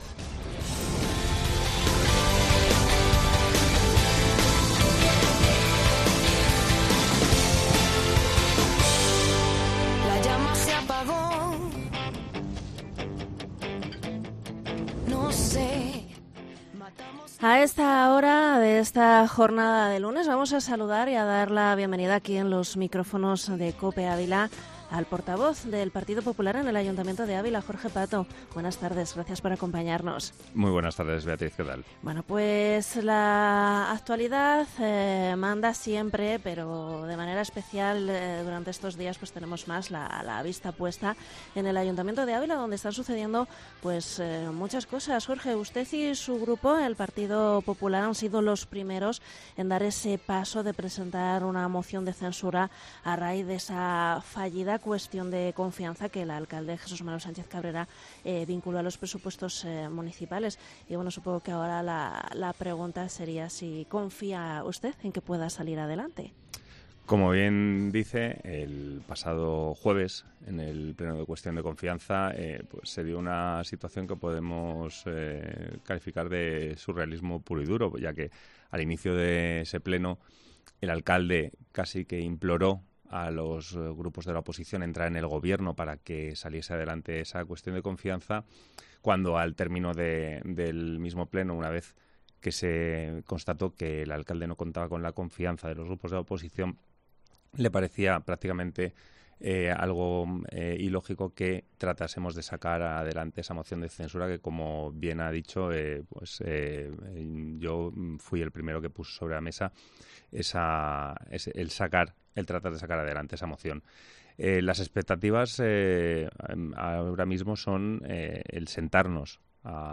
ENTREVISTA
Este lunes ha pasado por los micrófonos de COPE Ávila, el portavoz del PP en el Consistorio abulense, Jorge Pato.